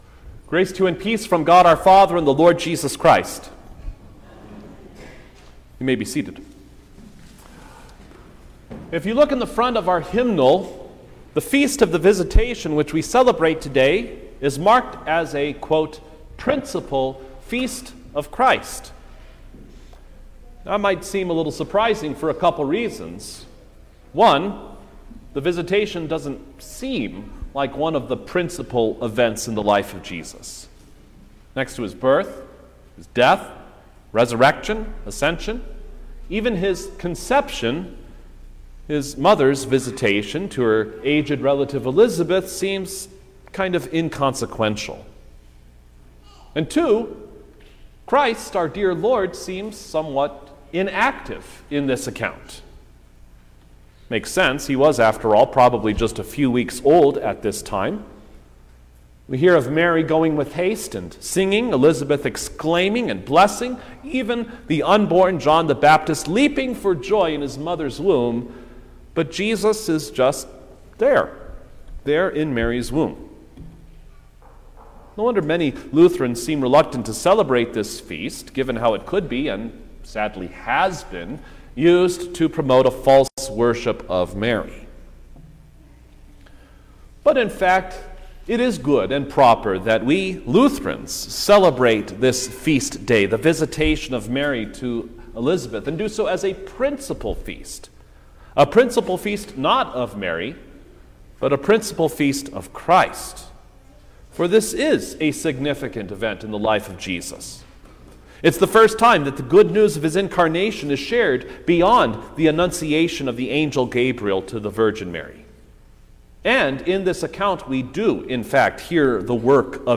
July-2_2023_The-Visitation_Sermon-Stereo.mp3